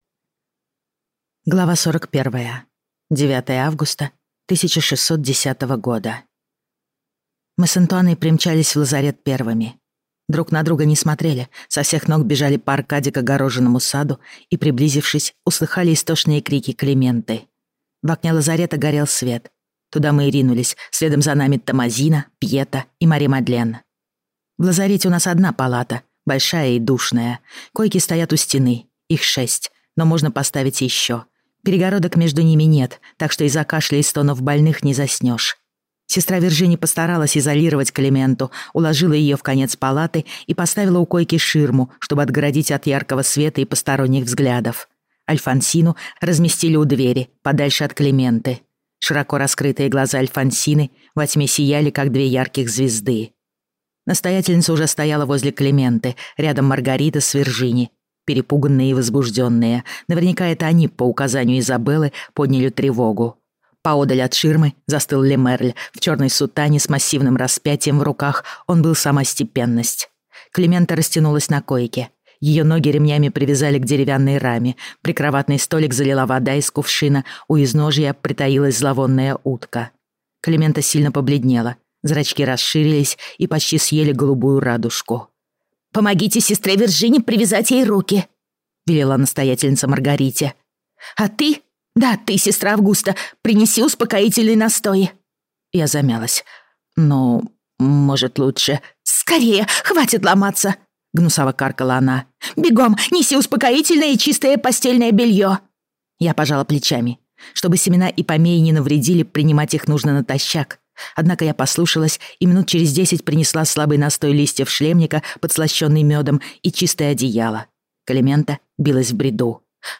Аудиокнига Блаженные | Библиотека аудиокниг